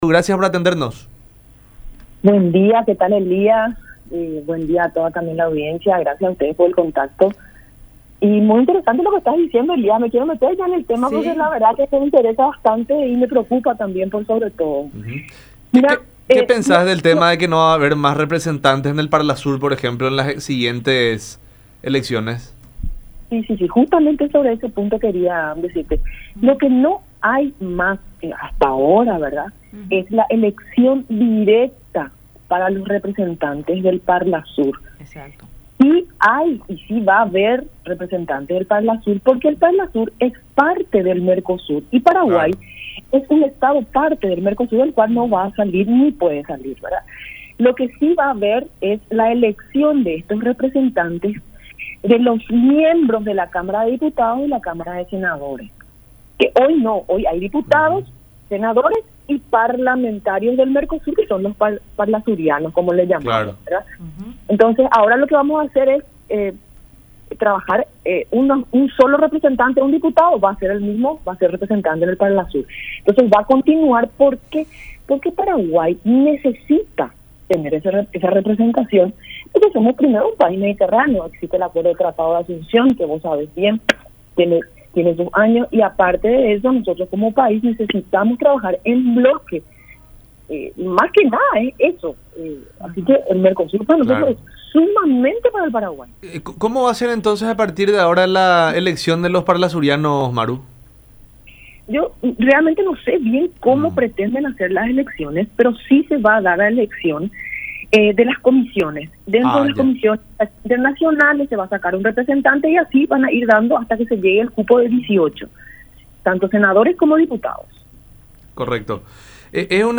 “El peaje de US$ 1,97 por tonelada impuesto por Argentina puede dejar un antecedente nefasto. No tenemos solo el Tratado del MERCOSUR, sino también el Acuerdo de Transporte Fluvial que se firmó en forma conjunta con Argentina, Uruguay, Brasil y Bolivia”, dijo Crichigno en diálogo con La Unión Hace La Fuerza por Unión TV y radio La Unión, subrayando que el MERCOSUR hace énfasis en el libre comercio regional en igualdad de condiciones.